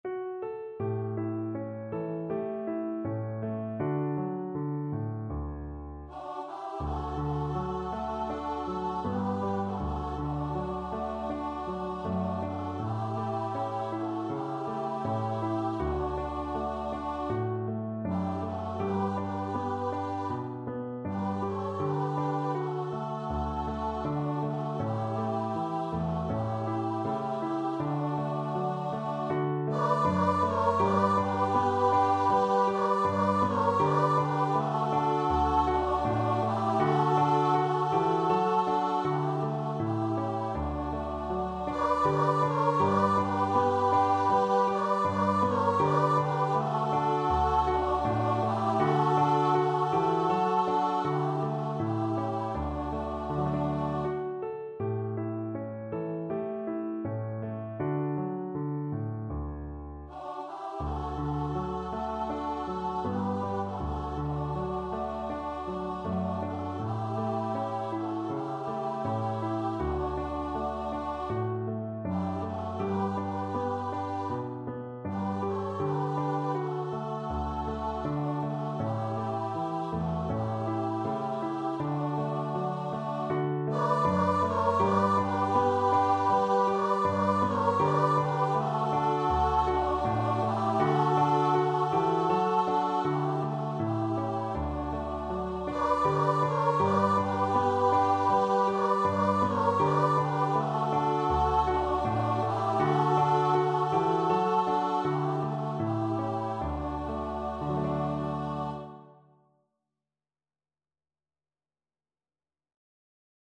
Free Sheet music for Choir (SA)
SopranoAlto
Traditional Music of unknown author.
D major (Sounding Pitch) (View more D major Music for Choir )
Andante
4/4 (View more 4/4 Music)